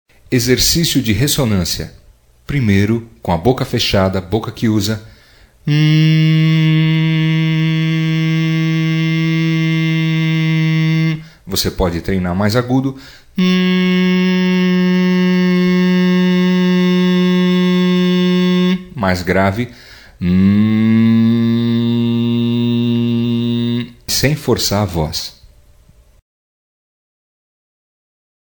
04-RESSONANCIA-01_Aquecimento_projecao-da-voz.mp3